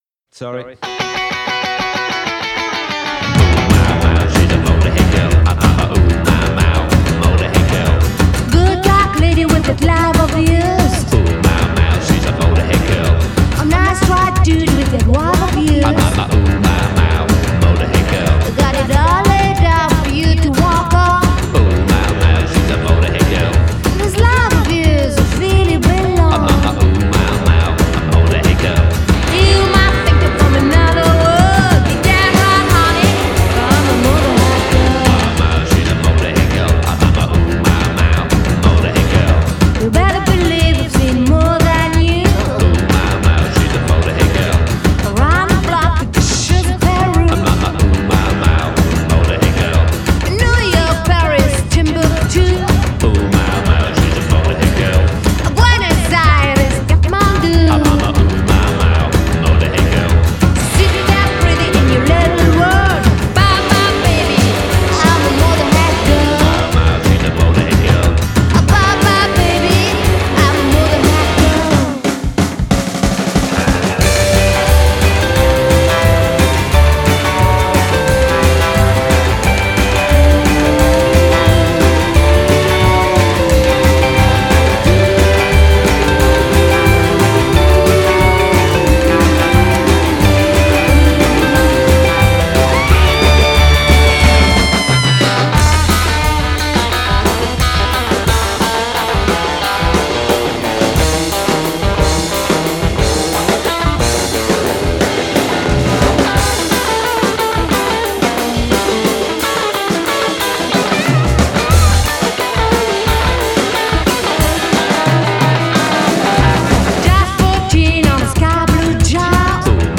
British/Swiss Rockabilly band
chanteuse